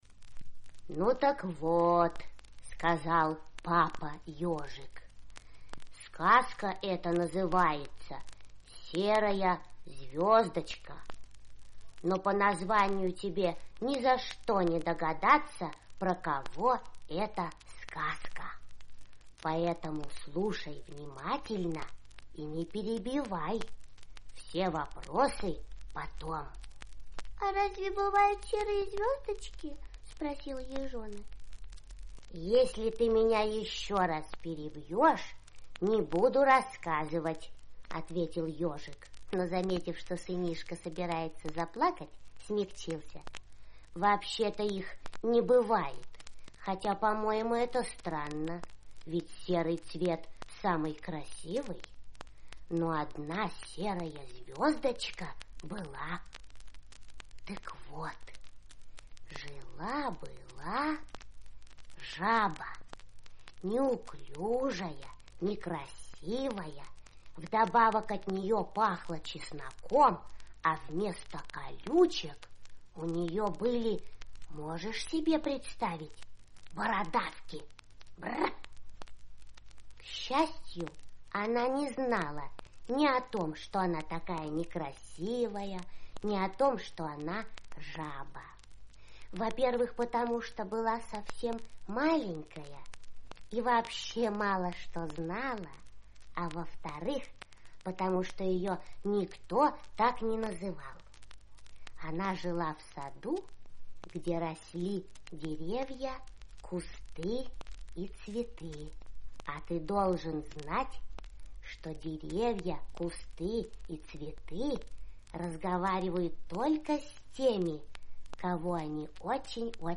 Серая звездочка - аудиосказка Заходера Б.В. Как папа Пжик рассказывал сыну Ежонку сказку про лягушонка с именем Серая Звездочка.